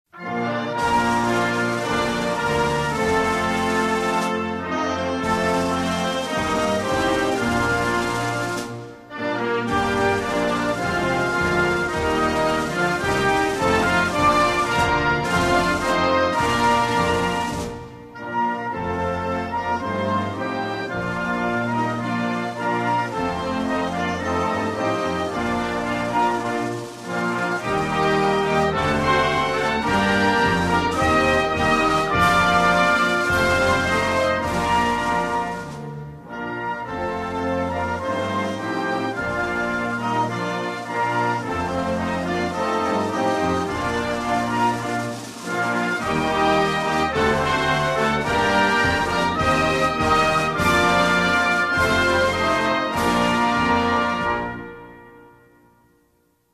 United_States_Navy_Band_-_Fatshe_leno_la_rona.mp3